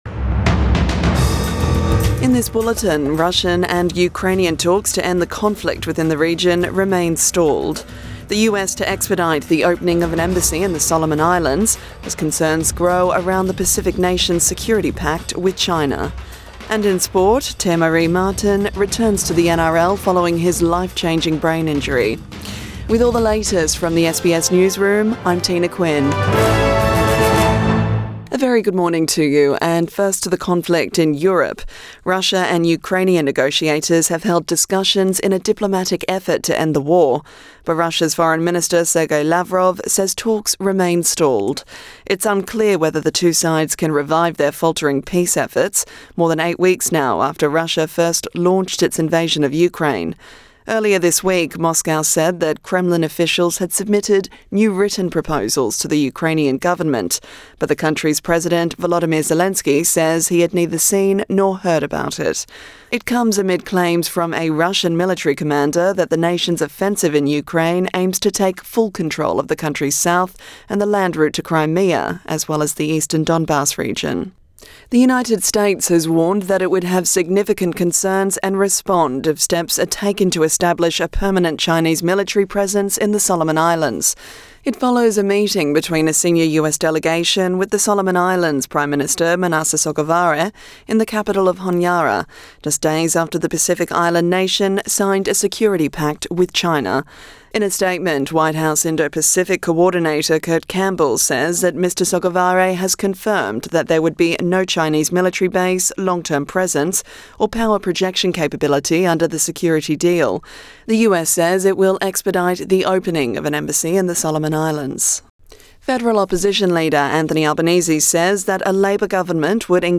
AM bulletin 23 April 2022